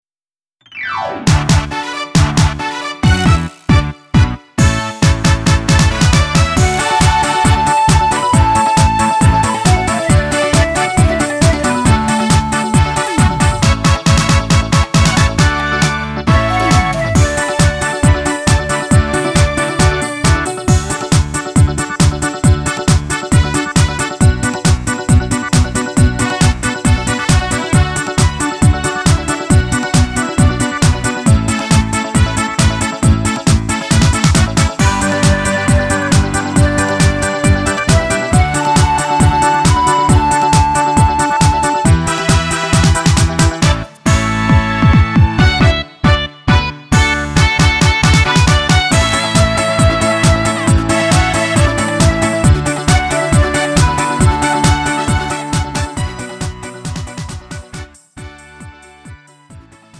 음정 -1키 3:29
장르 구분 Lite MR